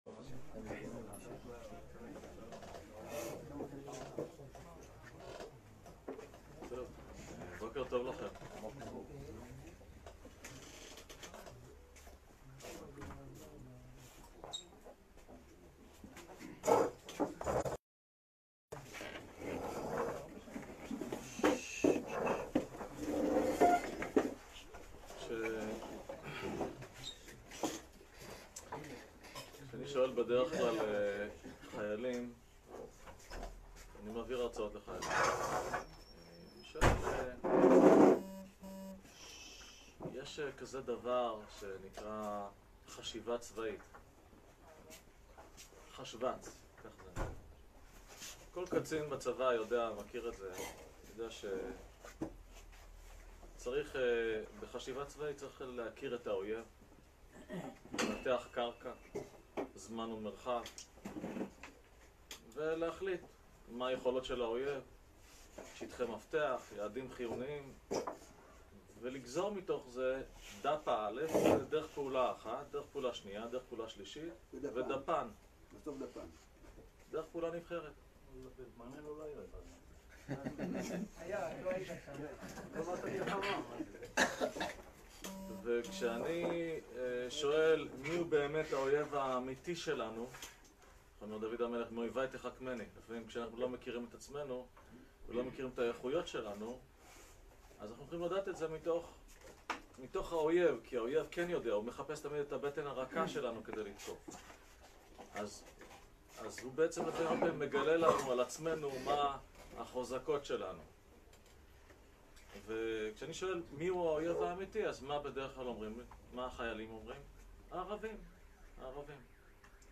הרצאה בראש העין